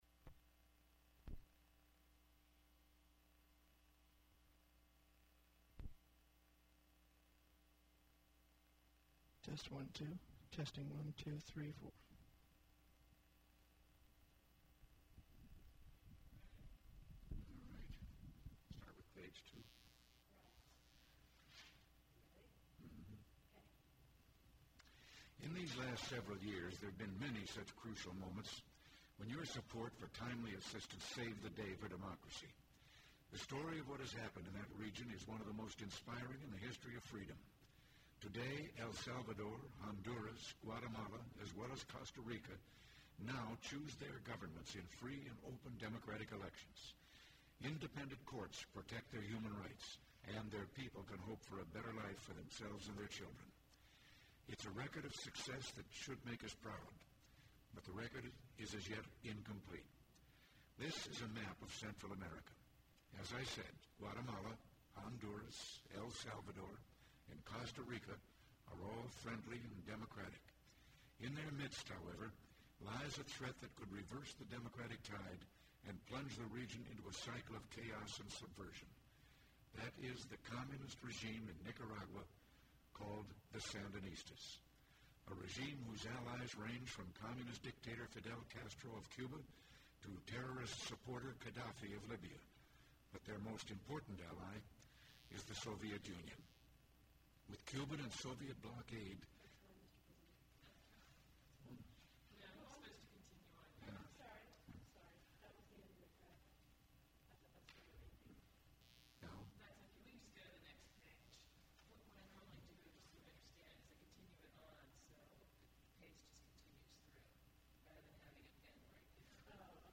President Reagan’s remarks at Taping, voice over for graphics for Central America address